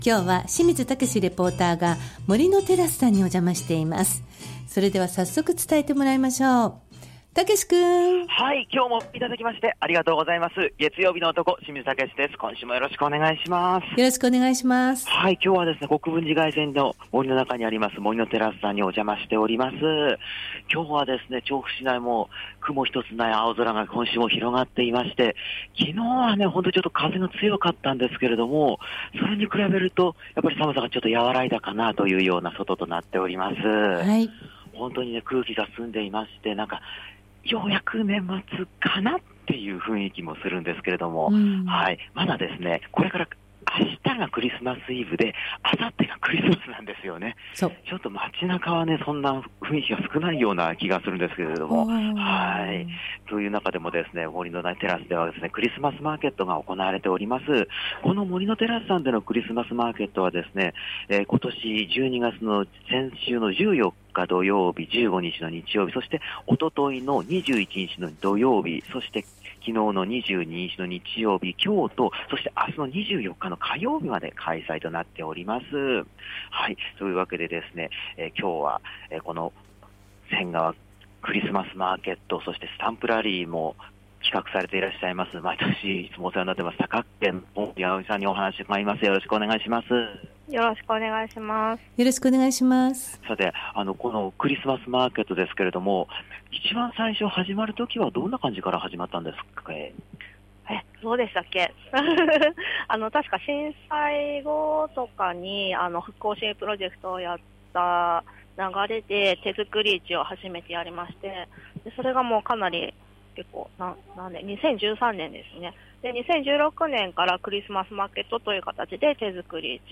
前日の風がおさまった青空の下からお届けした街角レポートは、恒例の『仙川クリスマスマーケット＆スタンプラリー2024』についてのレポートでした！！